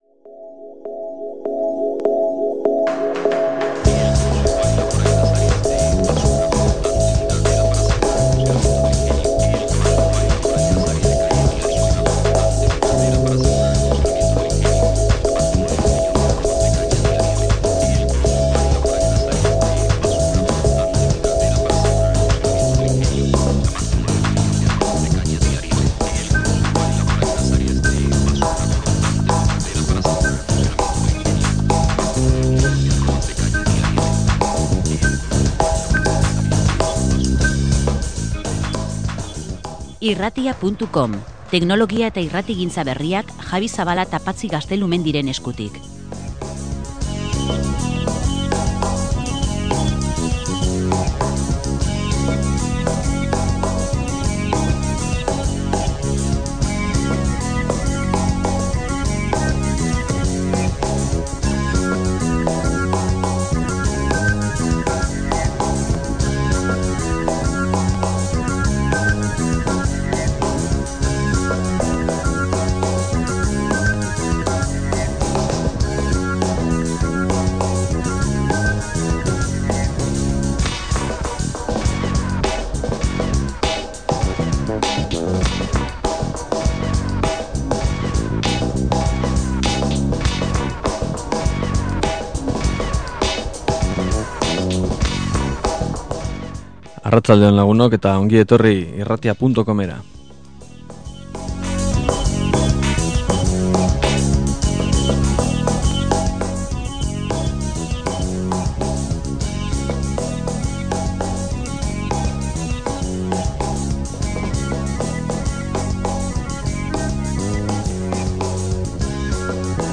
Entzungarri, eta guk saioan bertan eskaini ditugu, ordenadoretik entzunda.